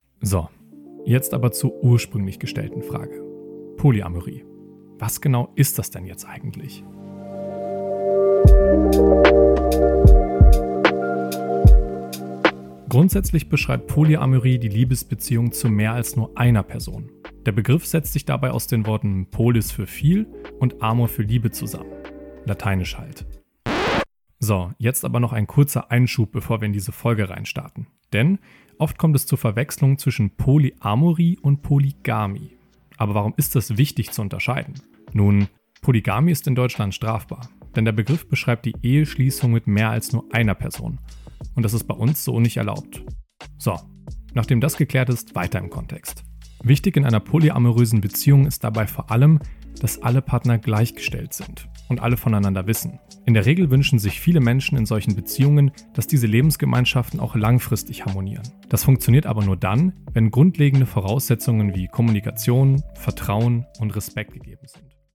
sehr variabel, markant
Mittel minus (25-45)
Audiobook (Hörbuch)